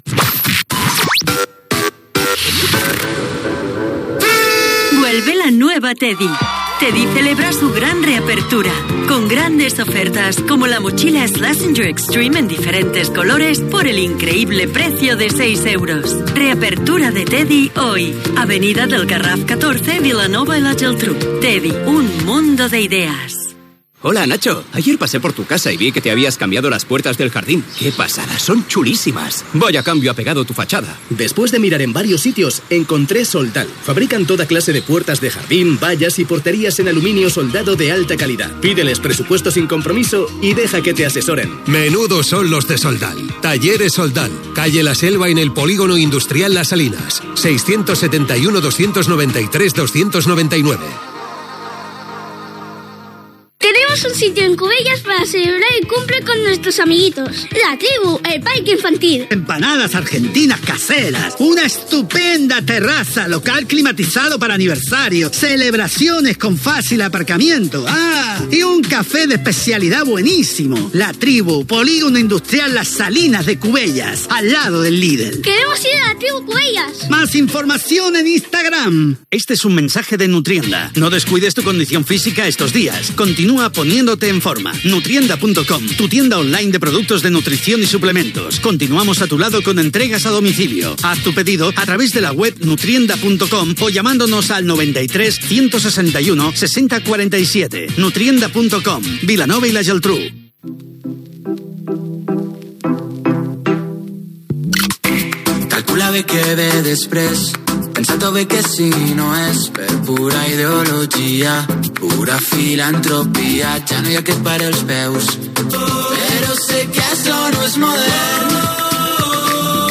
Publicitat, tema musical, indicatiu, tema musical, indicatiu, tema musical, hora, publicitat, tema musical, indicatiu, hora, tema musical
Musical
FM